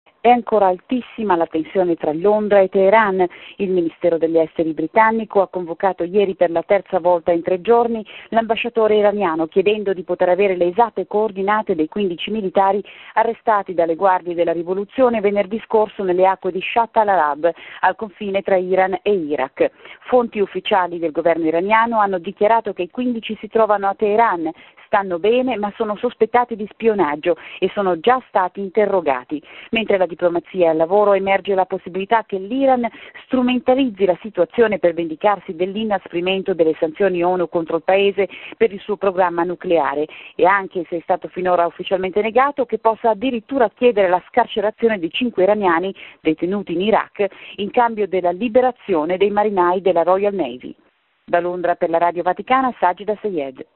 Da Londra